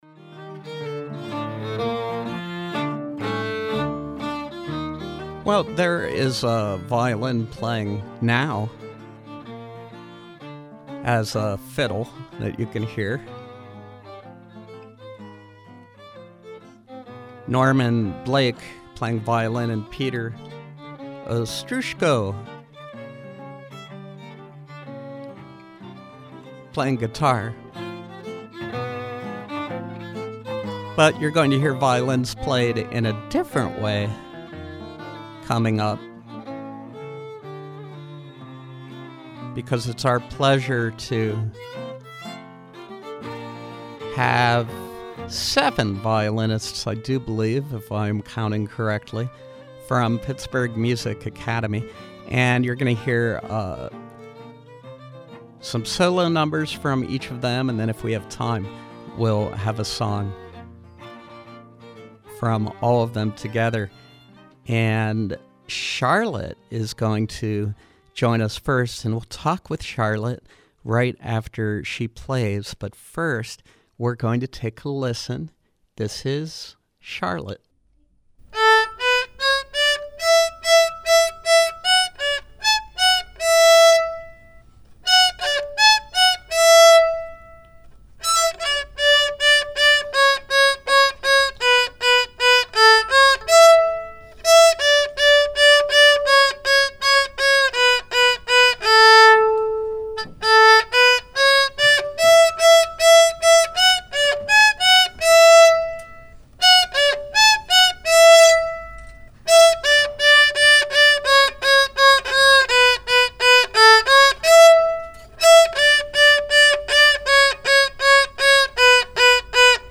From 2-7-15: Young violinists from Pittsburgh Music Academy.